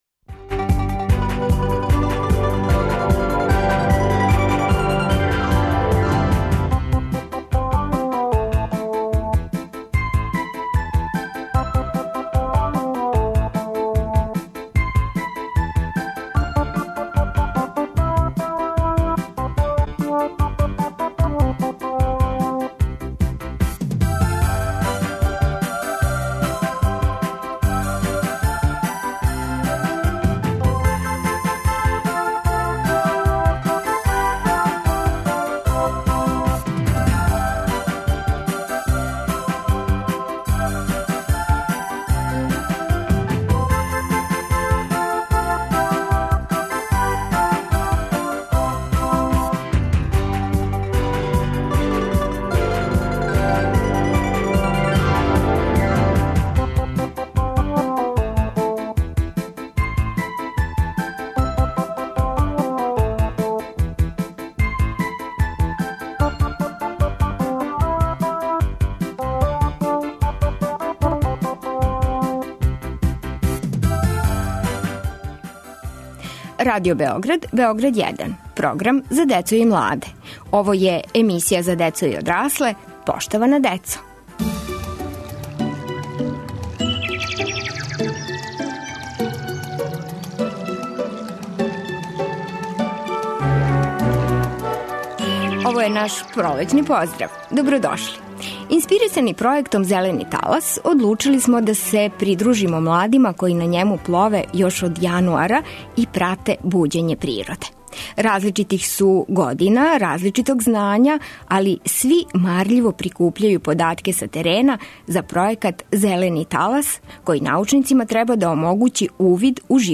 Њихови пријатељи из младих горана придружиће им се у студију, а од њих ћемо чути утиске са управо завршене акције сађења дрвореда на Ушћу.